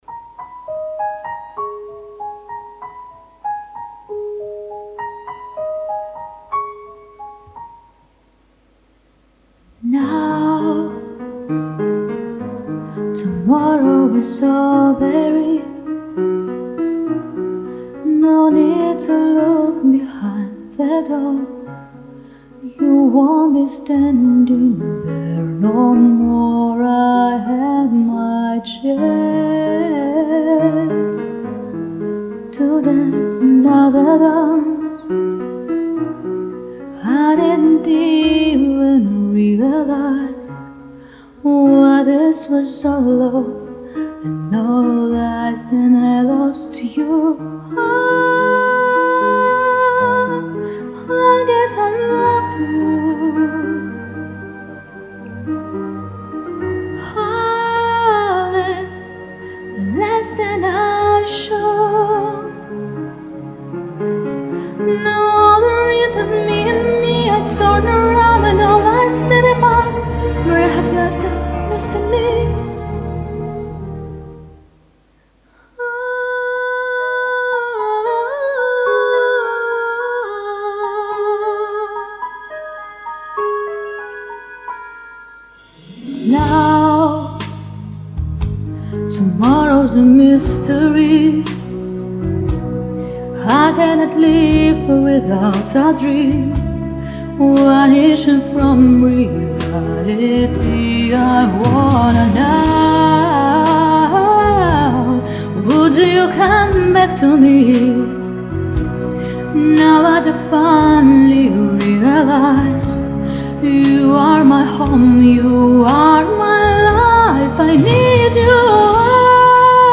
У обоих исполнительниц красивые, лирические голоса !
Может просто так слышно переход на резонаторы?))))